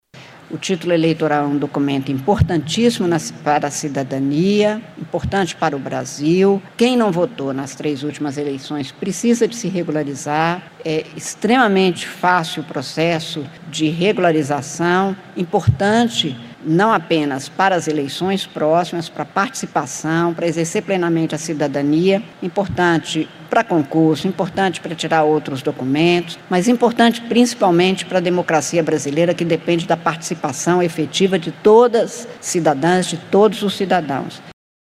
A presidente do TSE, ministra Cármen Lúcia, alerta quanto a importância de estar em dia com a Justiça Eleitoral.